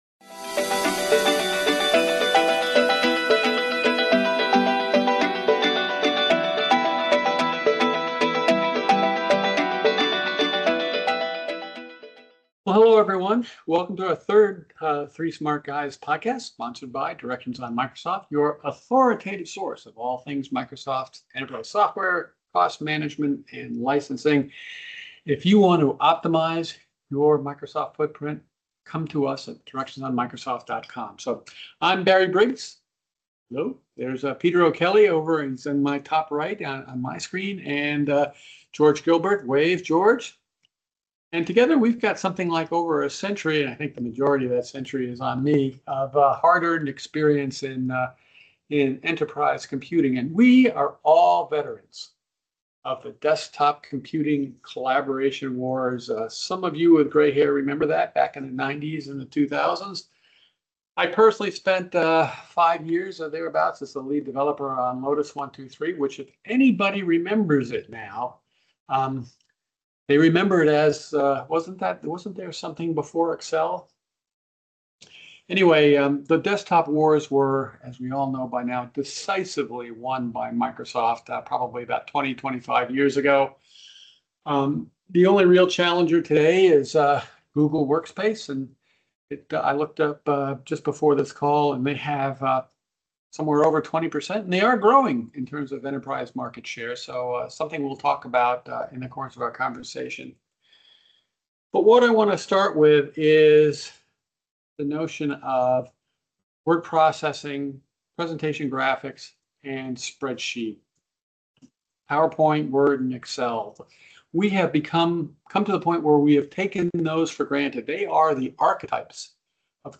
This week on the GeekWire Podcast: We hit the road for a driving tour of the week’s news, making stops at Starbucks, Microsoft, and an Amazon Fresh store in its final days.